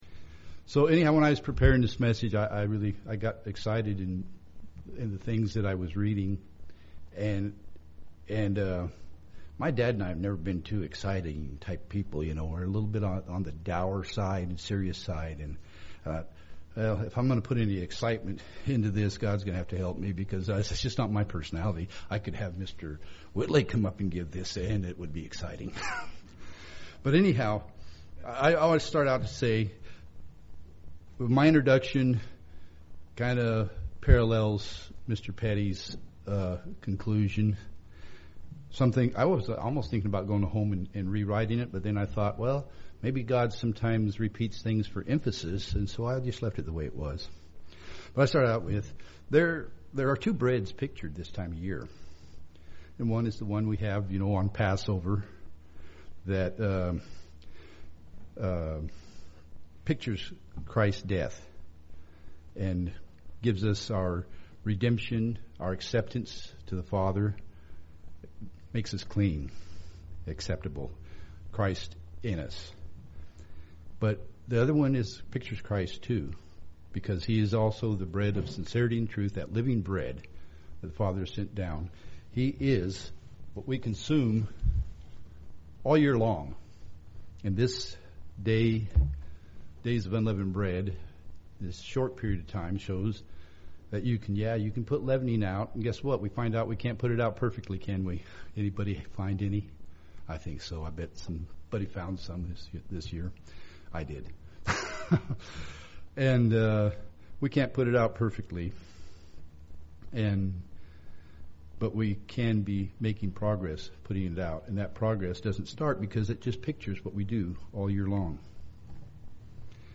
Sermons
Given in Medford, OR